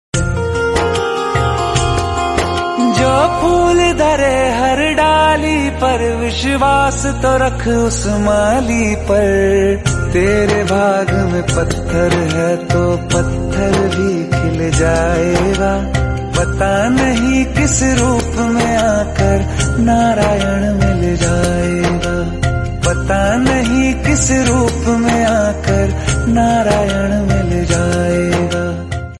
Om Namo Bhagavate Vasudevaya Mantra sound effects free download